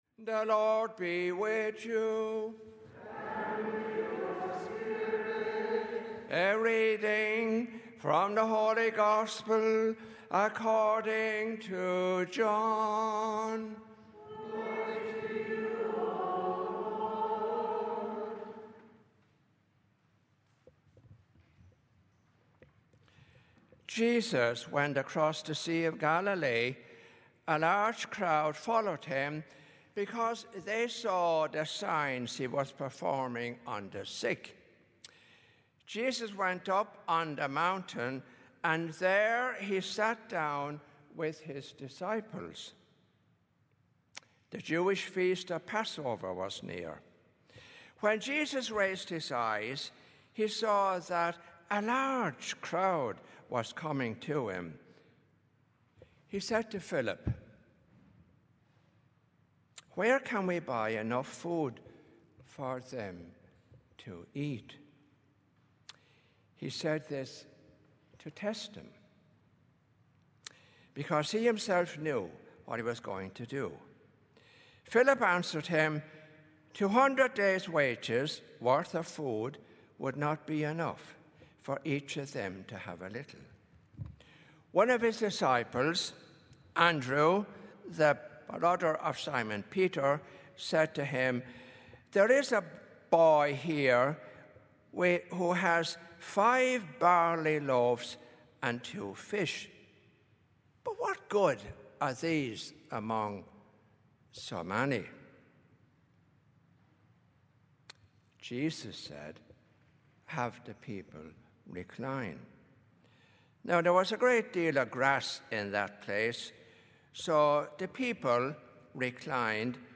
Gospel and Homily Podcasts
Holy Family Church Twenty-Fourth Sunday in Ordinary Time, September 12, 2021, 11:15 Mass Play Episode Pause Episode Mute/Unmute Episode Rewind 10 Seconds 1x Fast Forward 30 seconds 00:00 / 16:40 Subscribe Share